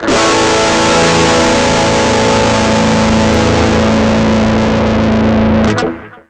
gtdTTE67006guitar-A.wav